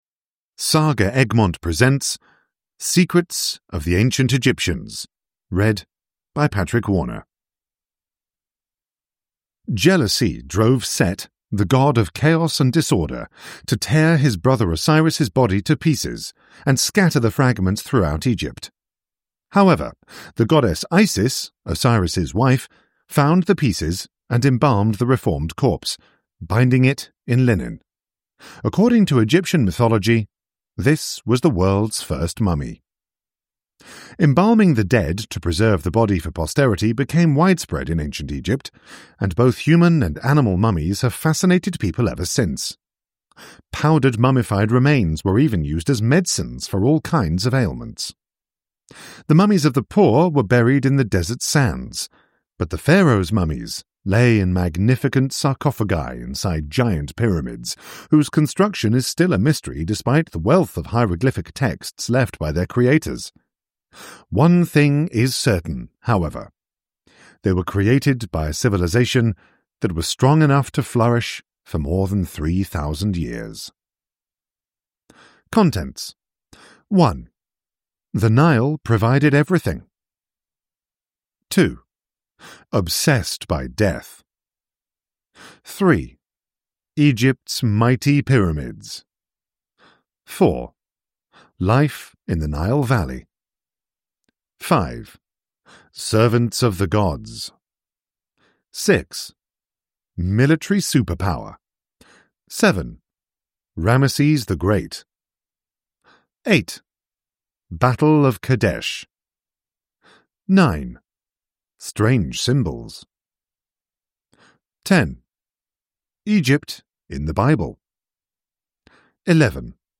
Secrets of the Ancient Egyptians – Ljudbok